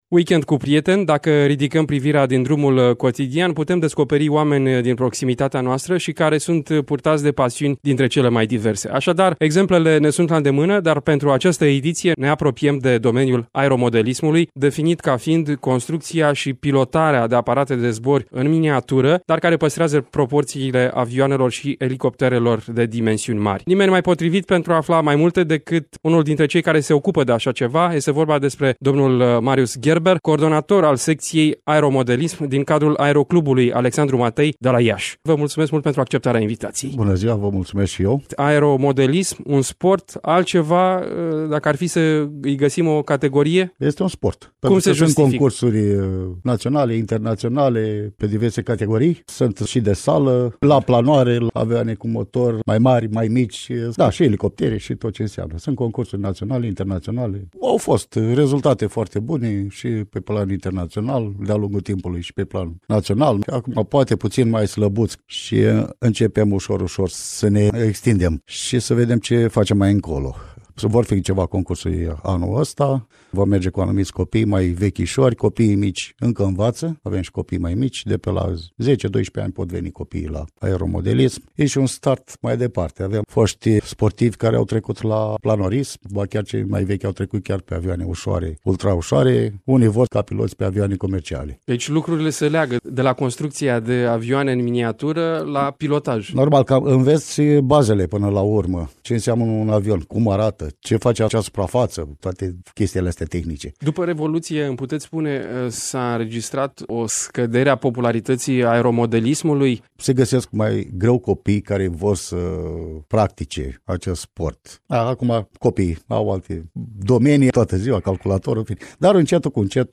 Aeromodelism, sport care-și caută adepți și în rândul copiilor. Interviu